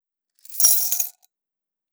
Base game sfx done
Saving Coins 01.wav